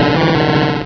Cri de Tarpaud dans Pokémon Rubis et Saphir.
Cri_0186_RS.ogg